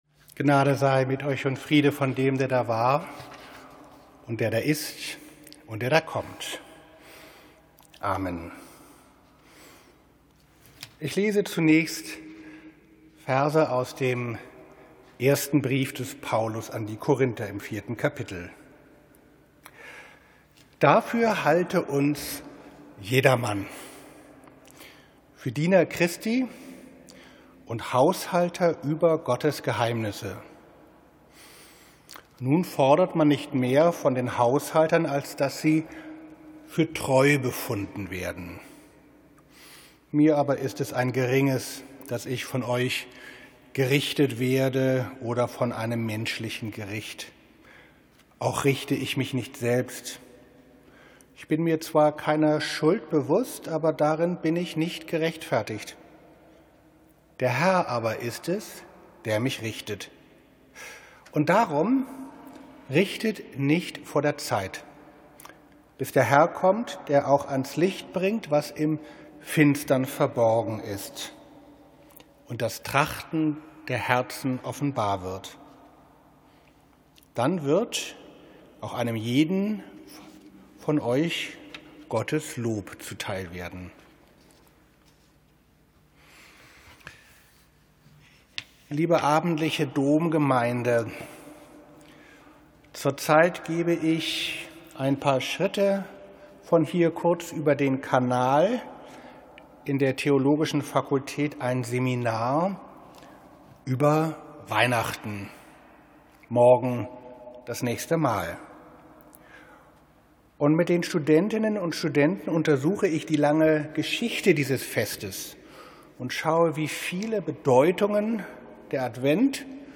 Predigten 2021
Silvester, 31. Dezember 2021, 17 Uhr Predigt über Matthäus 13, 24-30